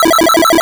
retro_synth_beeps_fast_03.wav